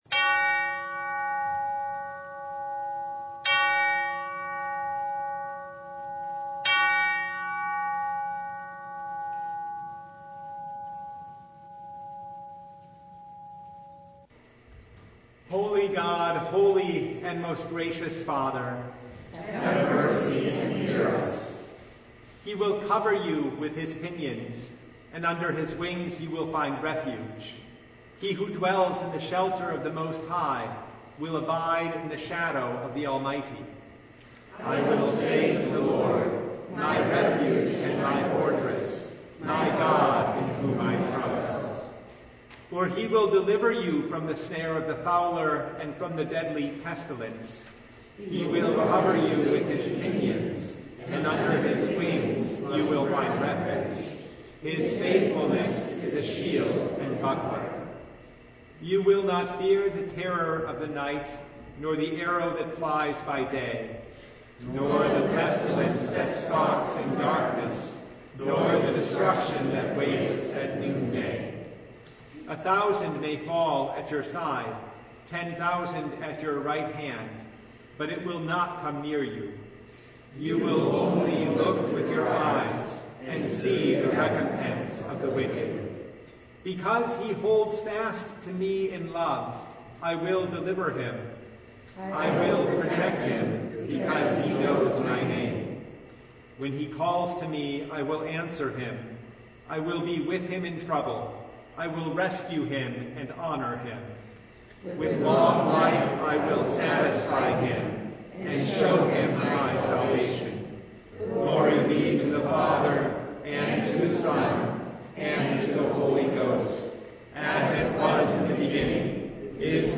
The Fourth Advent Noon Service (2022)
Full Service